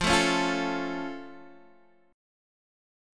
F+ chord